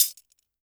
GLASS_Fragment_15_mono.wav